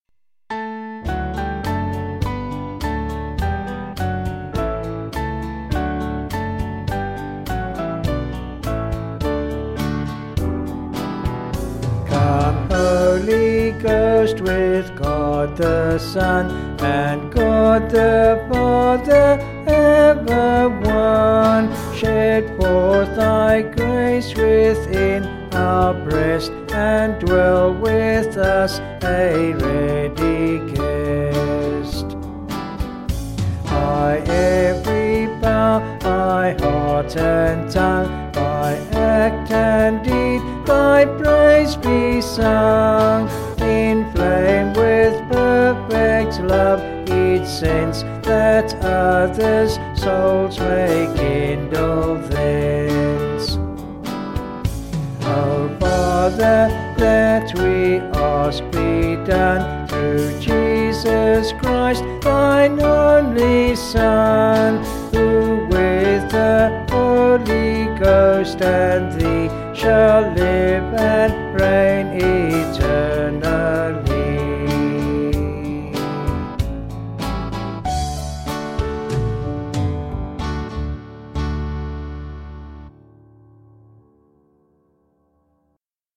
Vocals and Band   263.9kb Sung Lyrics